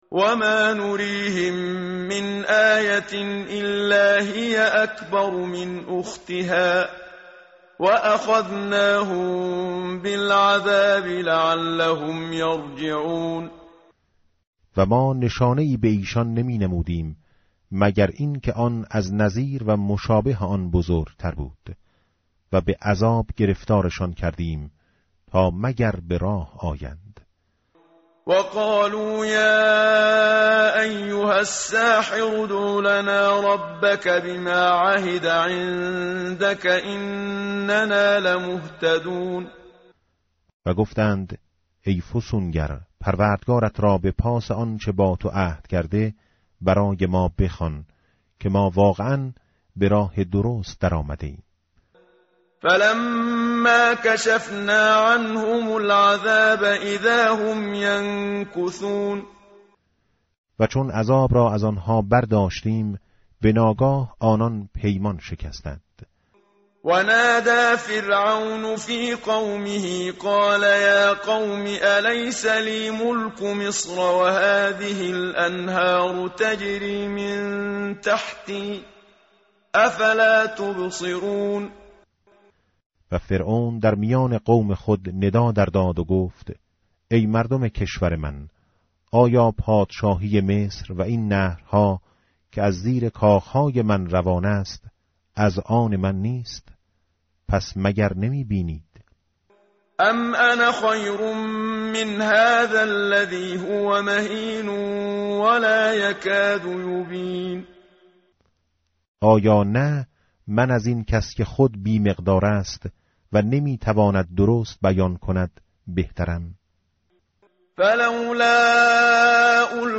متن قرآن همراه باتلاوت قرآن و ترجمه
tartil_menshavi va tarjome_Page_493.mp3